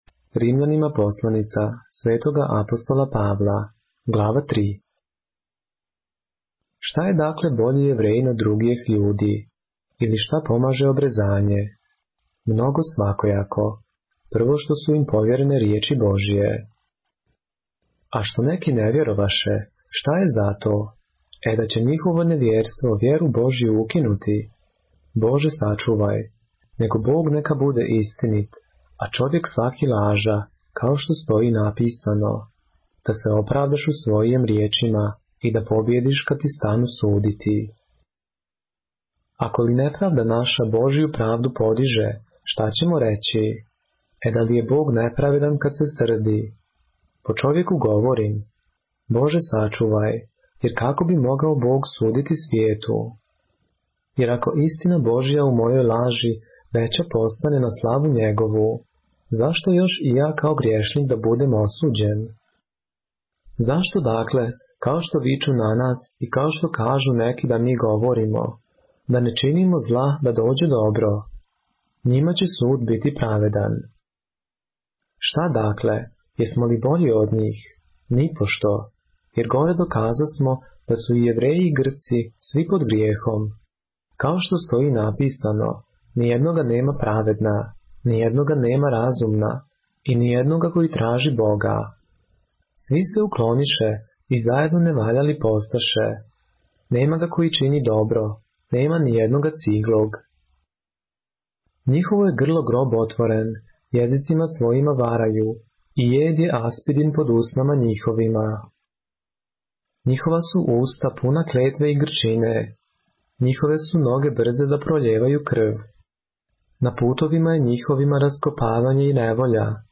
поглавље српске Библије - са аудио нарације - Romans, chapter 3 of the Holy Bible in the Serbian language